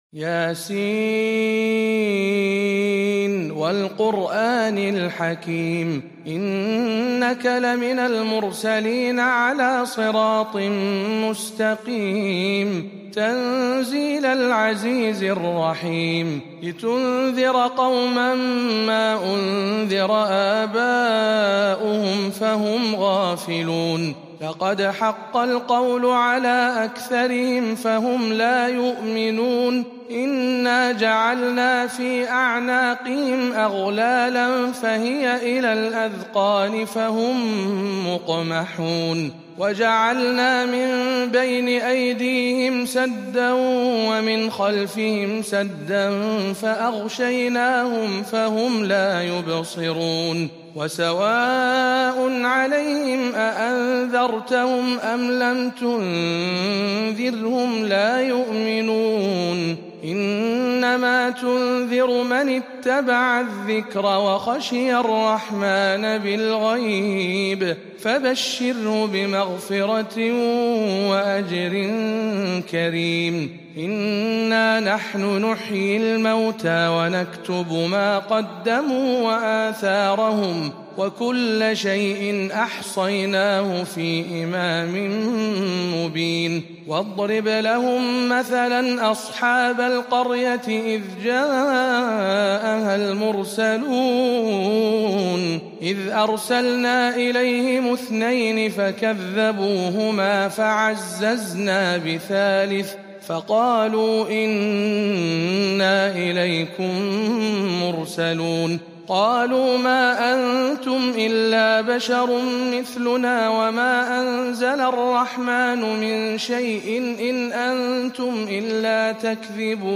سورة يس بجامع معاذ بن جبل بمكة المكرمة - رمضان 1440هـ للقارئ